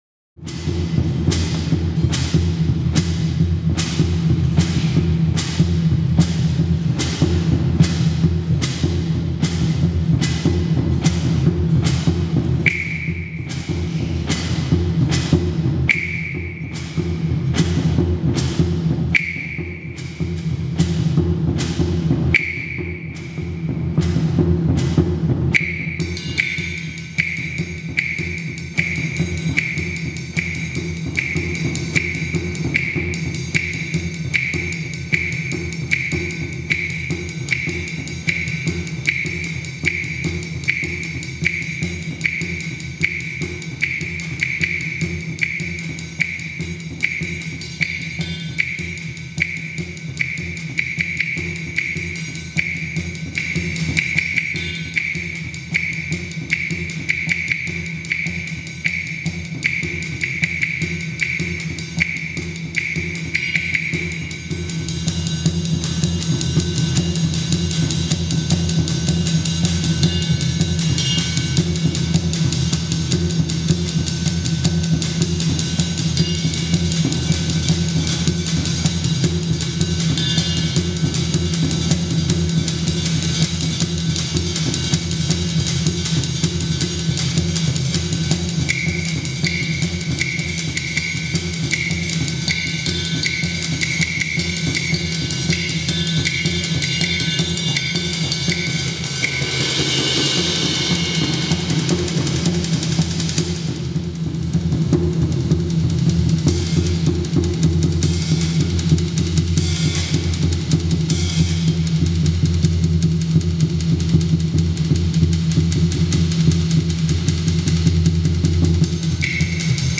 Ein Musik Clip